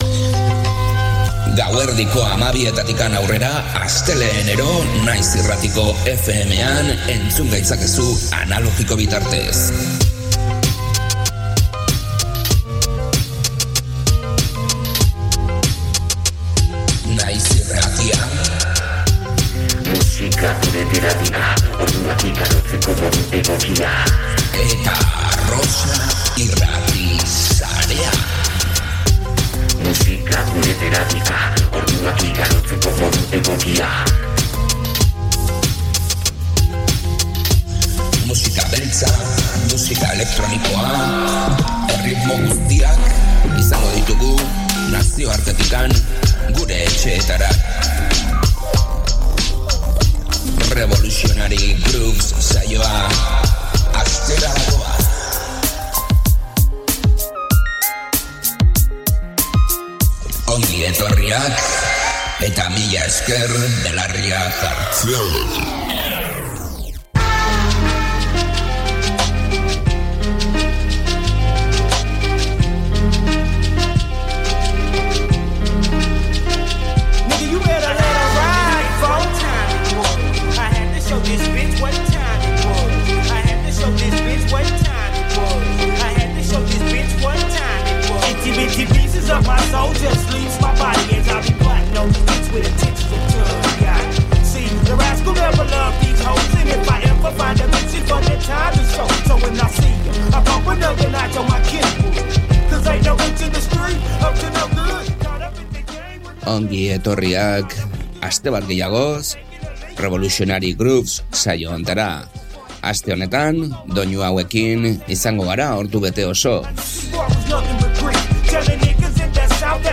G-funk doinuak